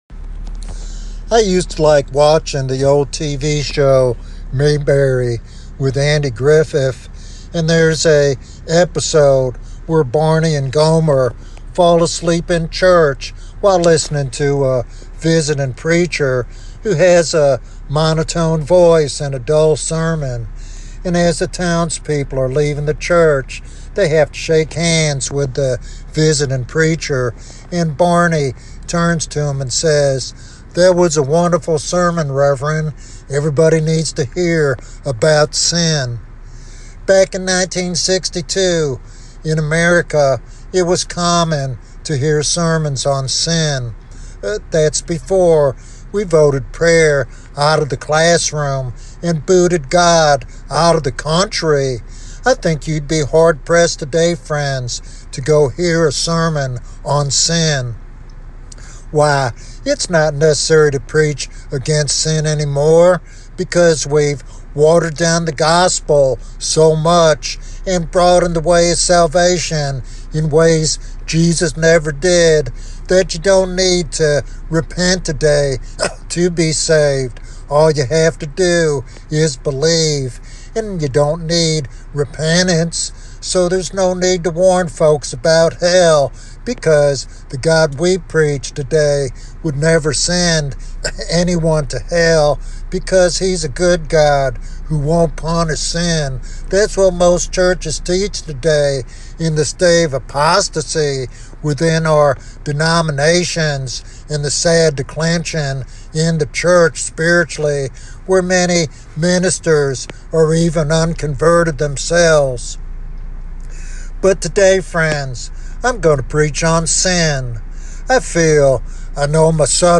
A Sermon on Sin